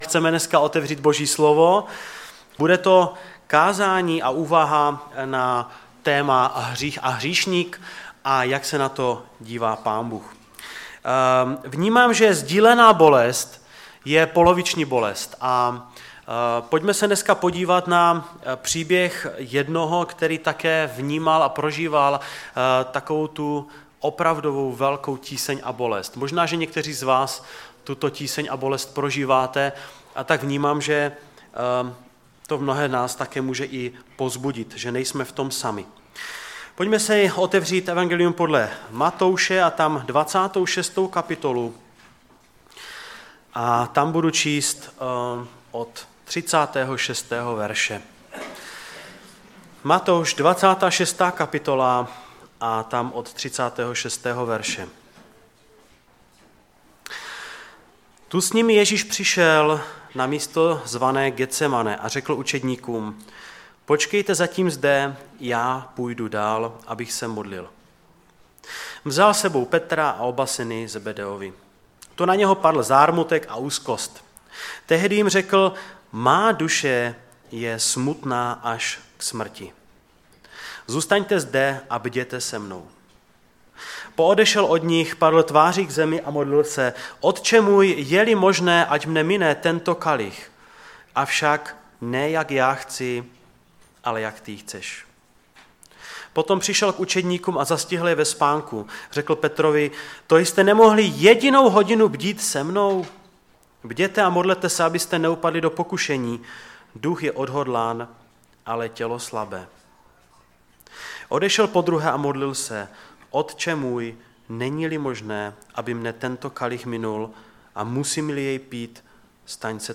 Kázání